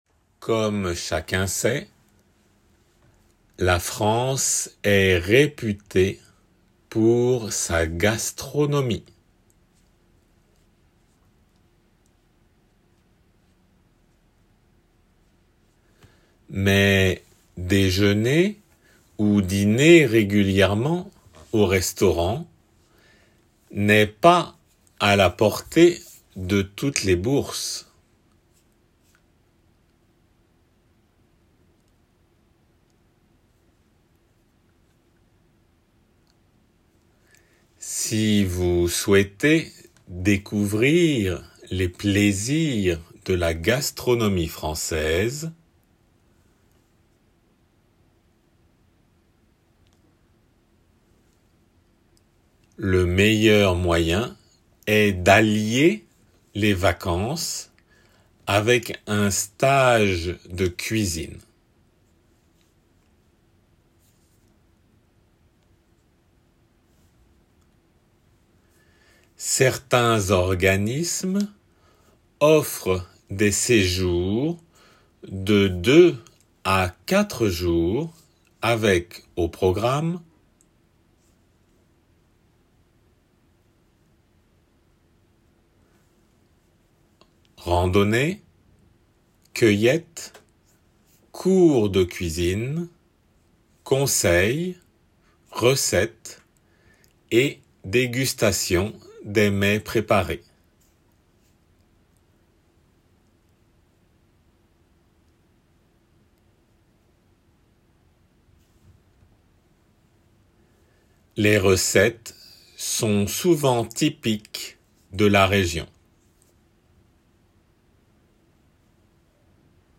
比較的優しい聞き取り練習です。